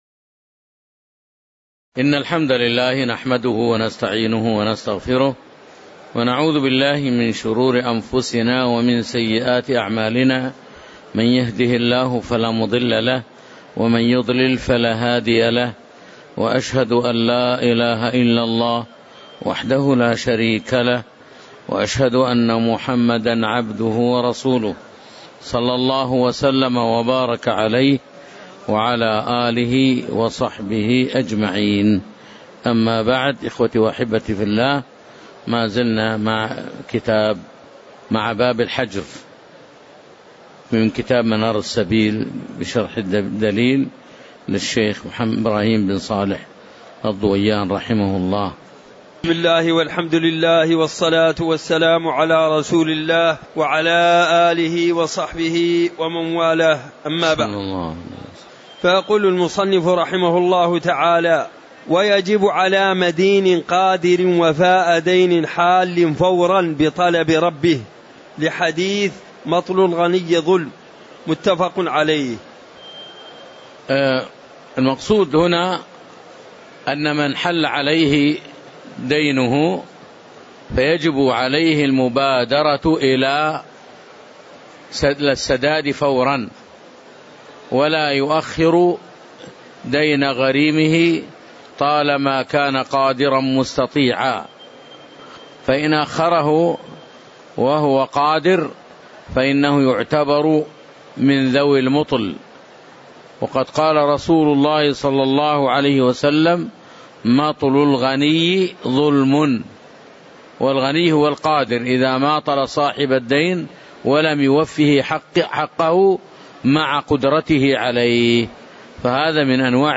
تاريخ النشر ٩ صفر ١٤٤١ هـ المكان: المسجد النبوي الشيخ